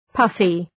Προφορά
{‘pʌfı}